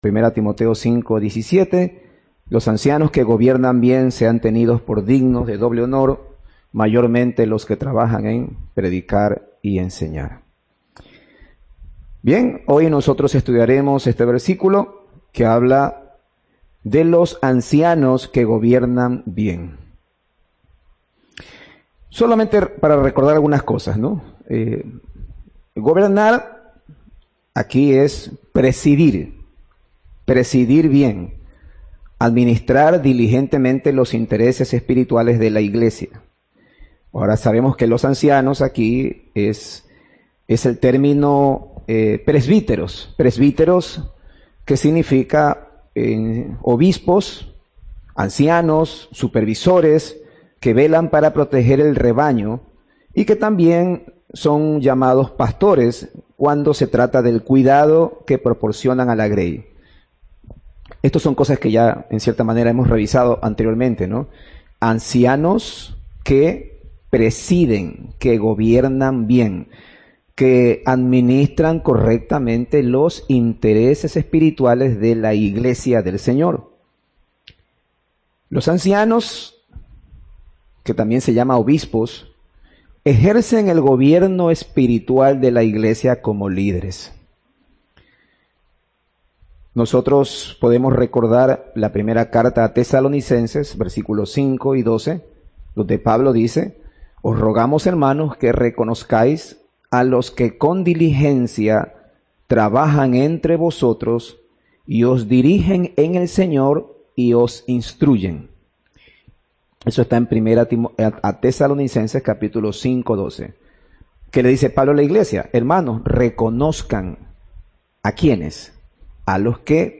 Audio del sermón
ancianos-con-oficio-distintivo.mp3